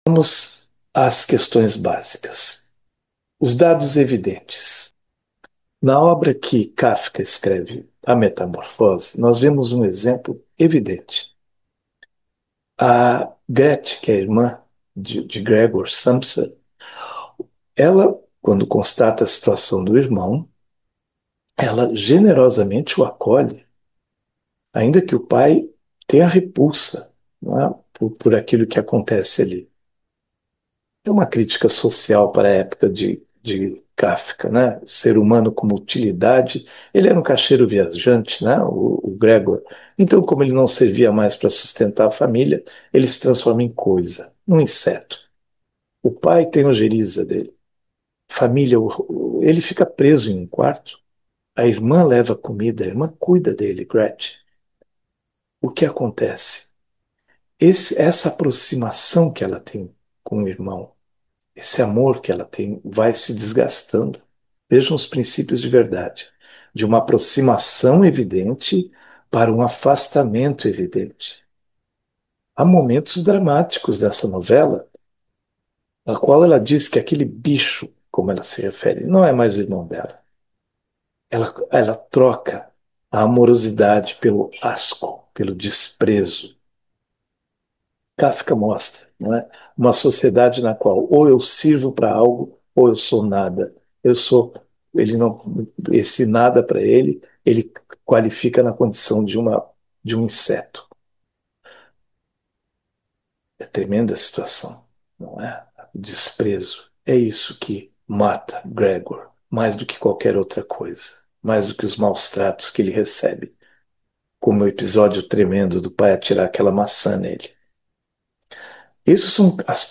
para ouvir o áudio editado